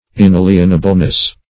Search Result for " inalienableness" : The Collaborative International Dictionary of English v.0.48: Inalienableness \In*al"ien*a*ble*ness\, n. The quality or state of being inalienable; inalienability.